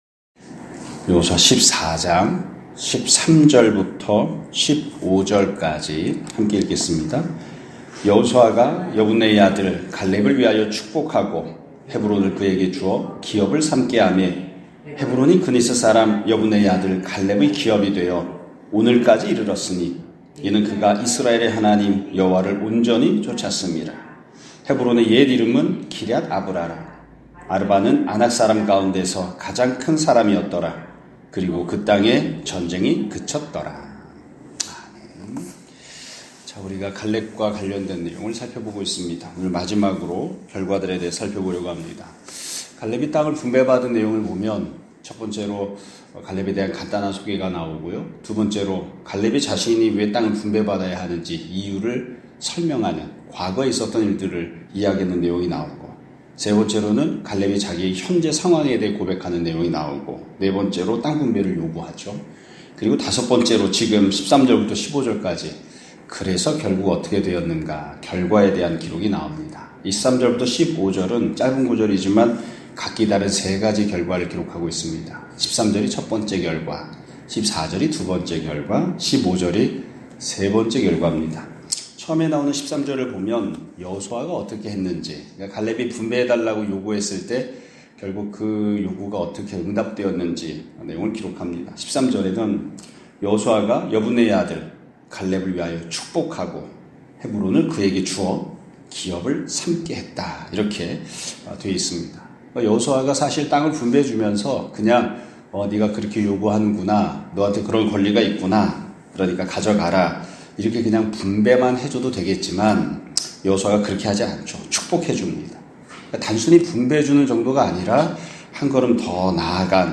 2024년 11월 29일(금요일) <아침예배> 설교입니다.